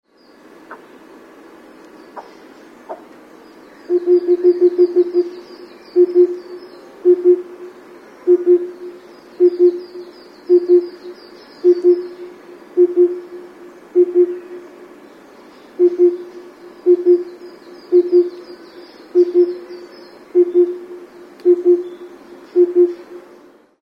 tsutsudori_s1.mp3